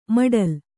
♪ maḍal